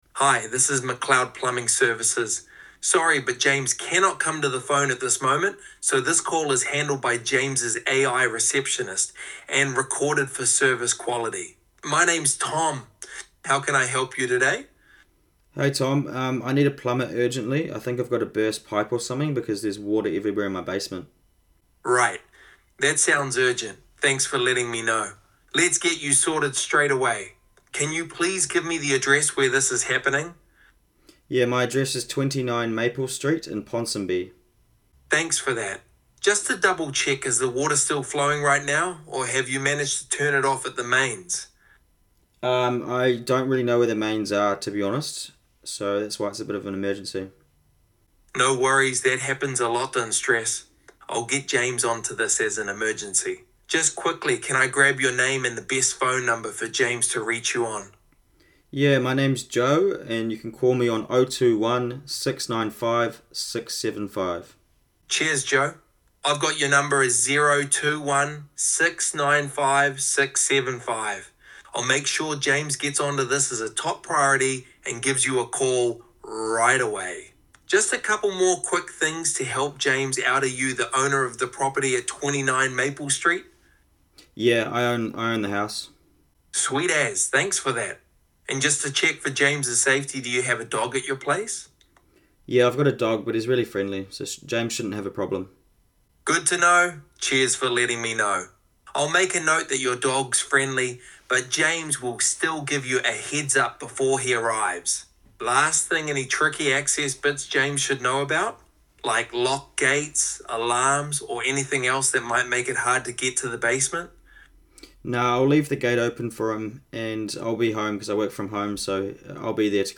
Sounds Completely Human
Emergency call handling demonstration
🎧 Customer inquiry – Burst pipe emergency
Demo Note: This conversation shows how TradieAssistance handles emergency plumbing calls, gathering essential details like location, urgency, and contact information while maintaining a professional tone.